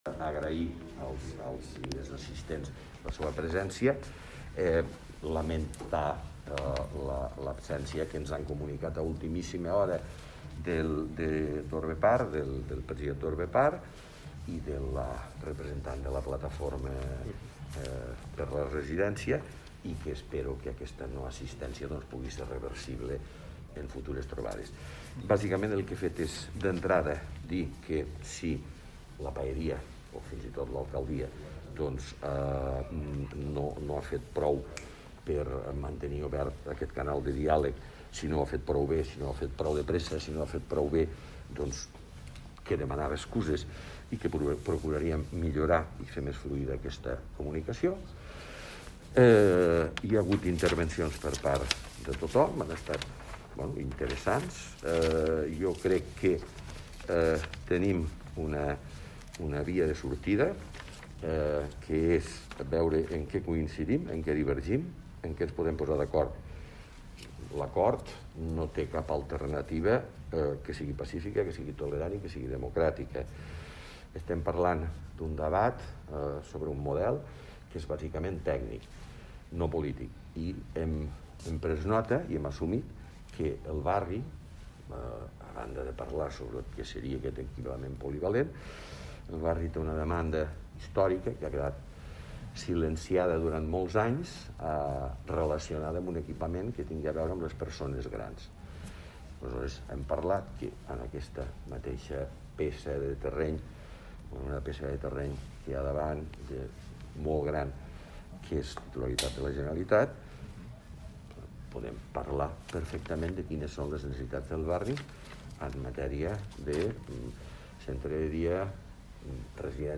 tall-de-veu-del-paer-en-cap-miquel-pueyo-sobre-la-reunio-de-lecmu-de-pardinyes